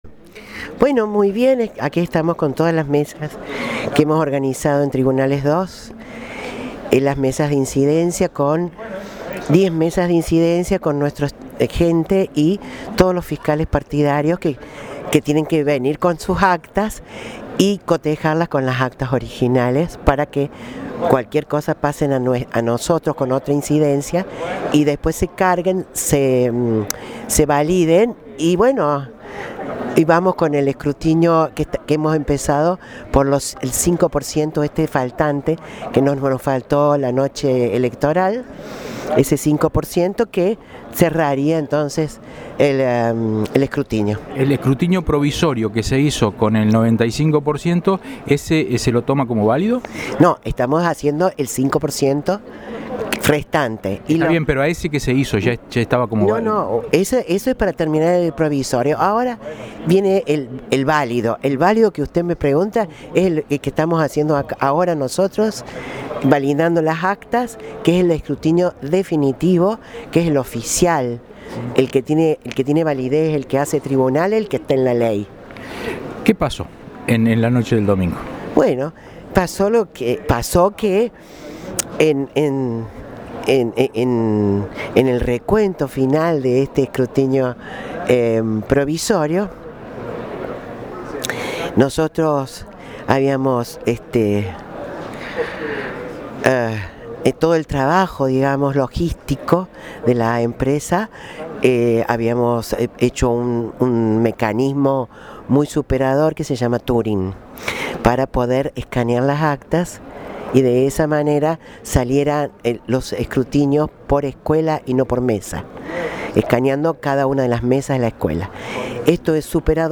Audio: Dra. Marta Vidal (Presidenta del Tribunal Electoral Provincial).